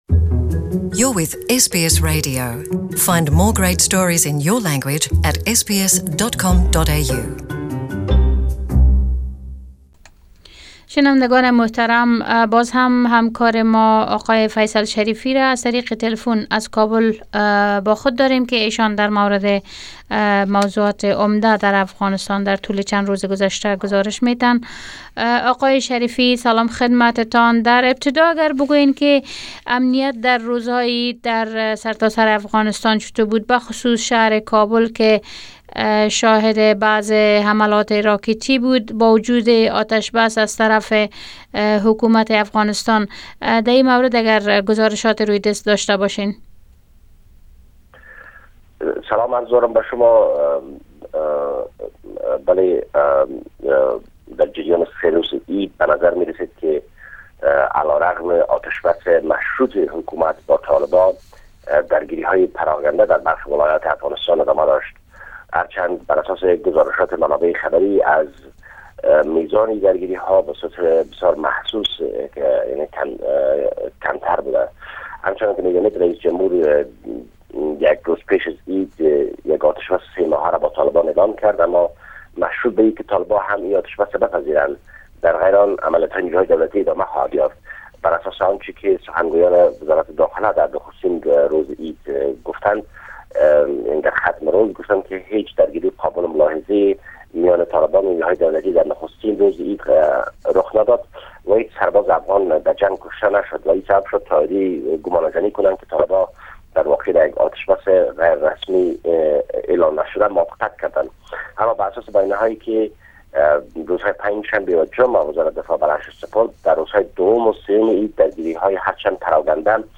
REPORT FROM KABBUL